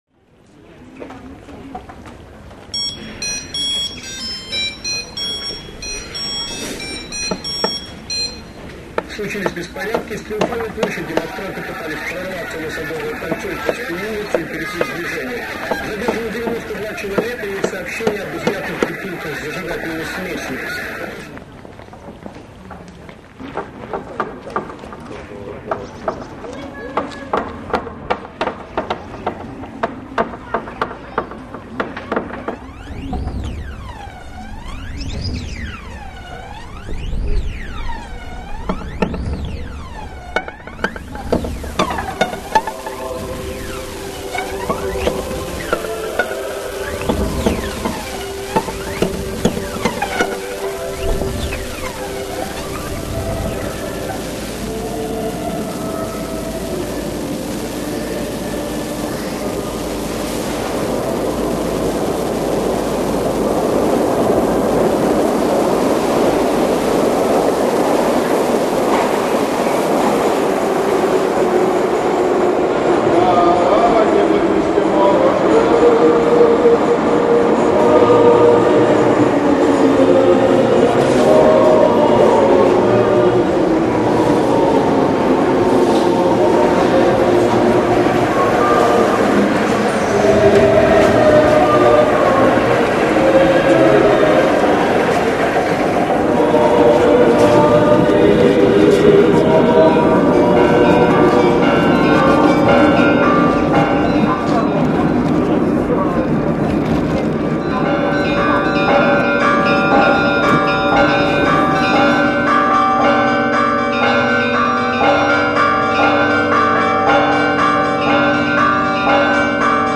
experimental music